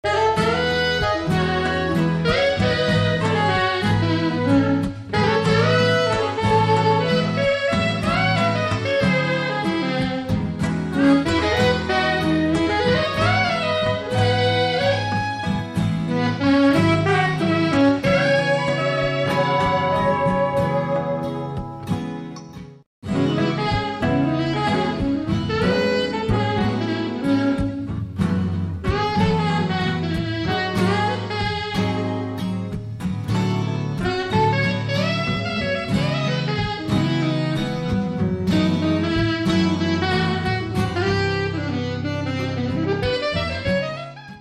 Alto Saxophone:
It is an acoustic marriage of the Sanborn style and a Brazilian acoustic guitar approach.